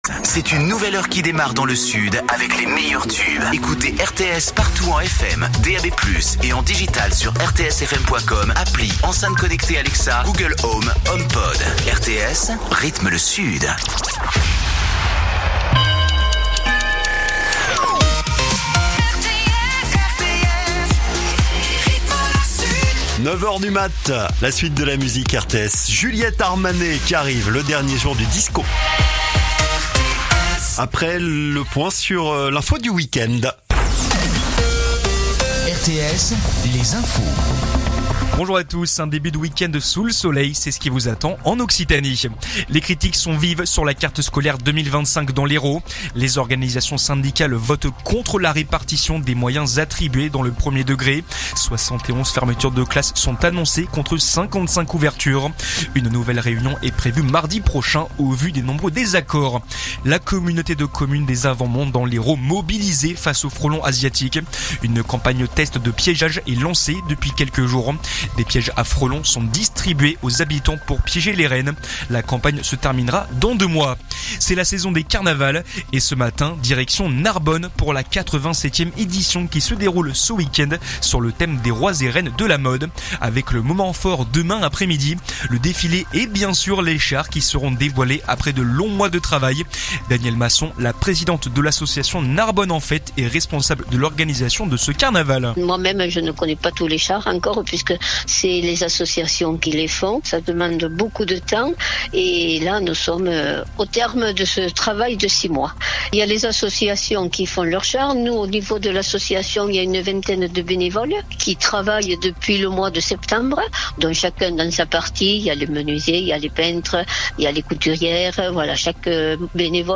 info_narbonne_toulouse_309.mp3